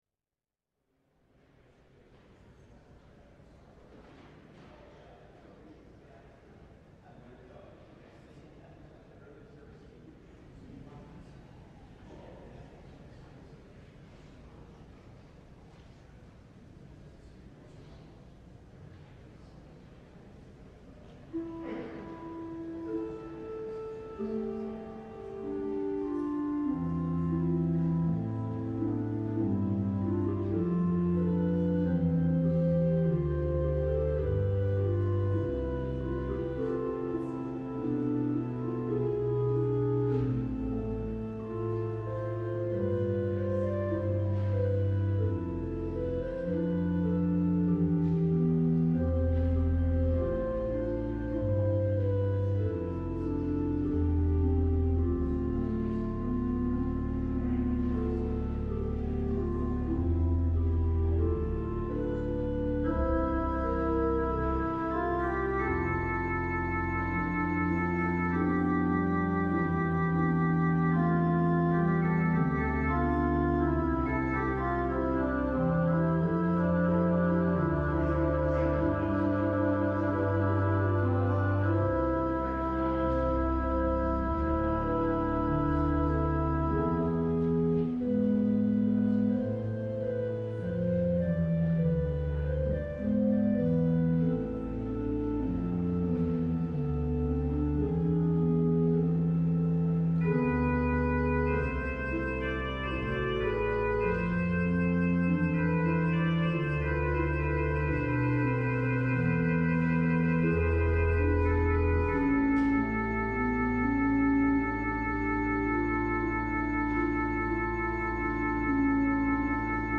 LIVE Morning Service - Cross Words: The Atoning Sacrifice